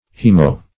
Haemo- \H[ae]m"o-\ (h[e^]m"[-o]- or h[=e]"m[-o]-), prefix.